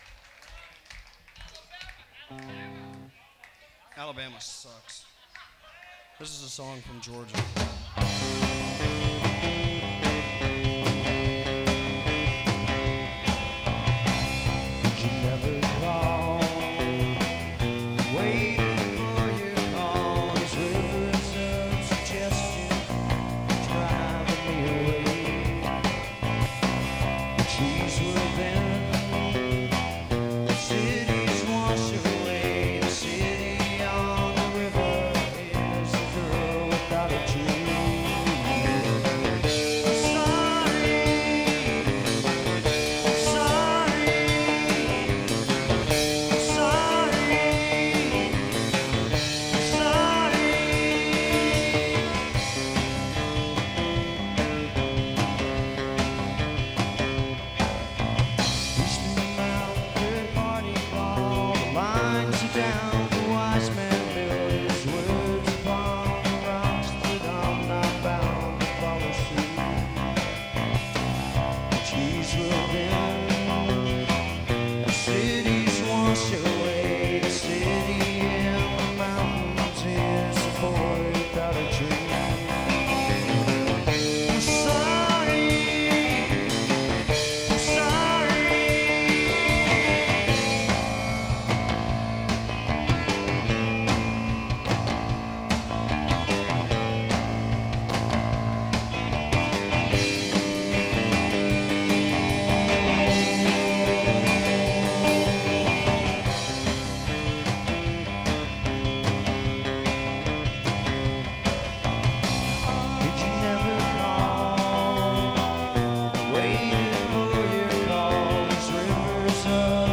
This review is on the soundboard show.